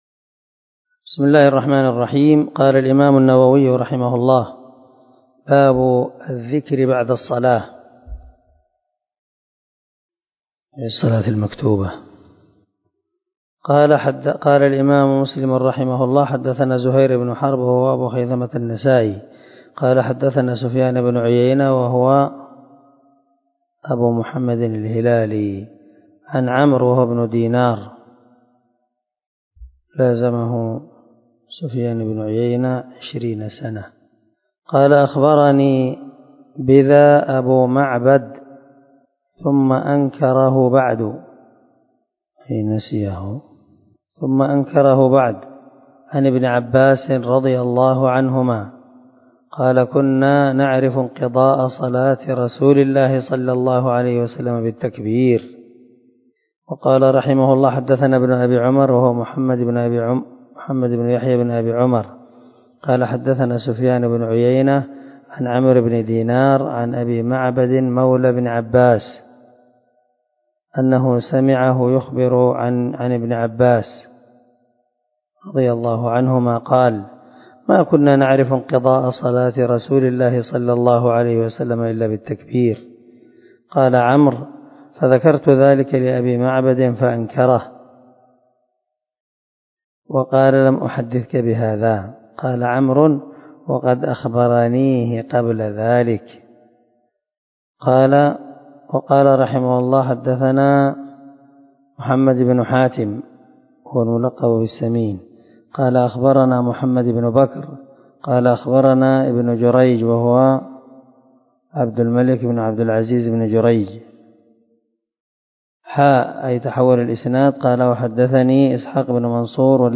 370الدرس 42 من شرح كتاب المساجد ومواضع الصلاة حديث رقم ( 583 ) من صحيح مسلم